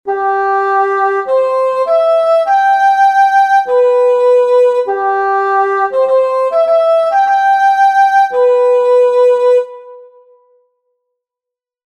Cercar della nota, Suchen des Tons. Ein Ausdruck aus der Gesanglehre; bedeutet einen Nachschlag von einer Note auf die folgende – oder die Vorausnahme des nachfolgenden Tones auf die Silbe des vorhergehenden.
cercar-della-nota-gathy1840.mp3